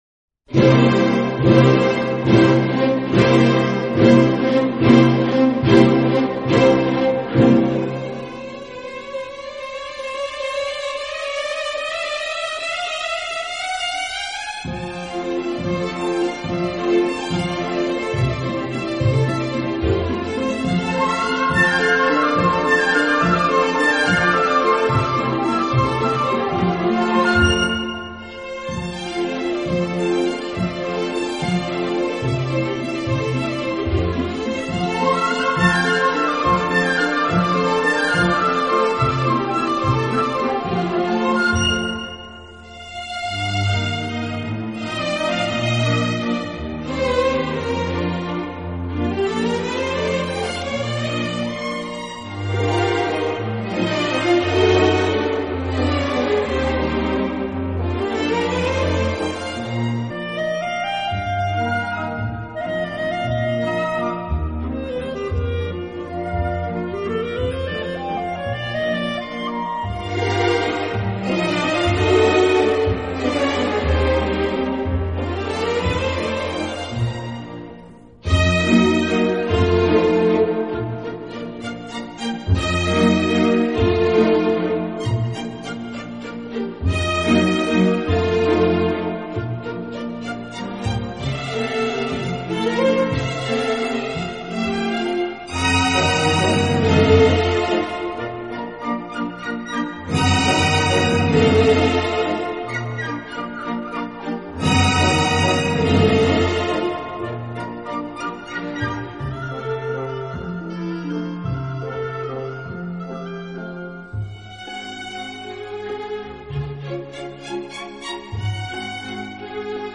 好处的管乐组合，给人以美不胜收之感。